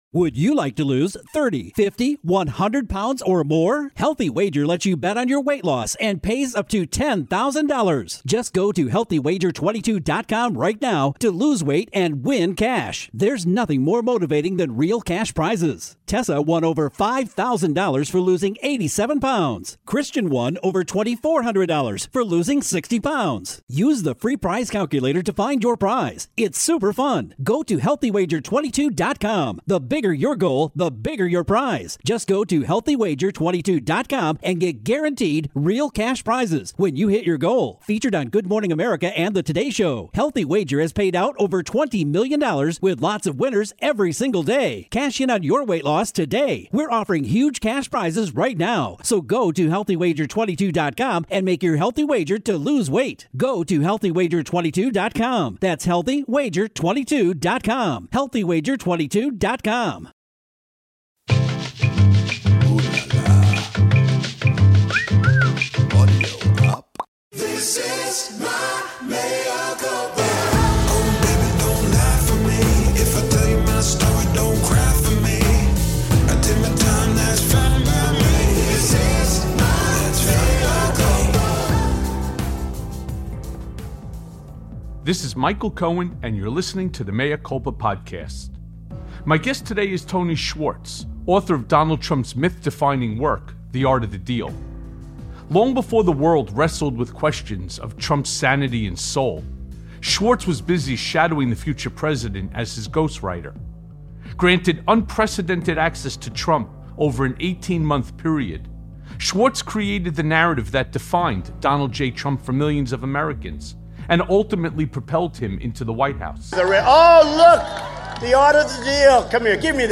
An exclusive interview with Tony Schwartz, the author behind Donald Trump's myth defining work, Trump: the Art of the Deal.